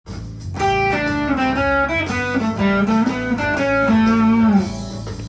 ①のフレーズは、Aマイナーペンタトニックスケールで始まり
Aメジャーペンタトニックスケールを弾いた後にAミクソリディアンスケール
３つ混ぜると違和感があると思いきや自然に聞こえます。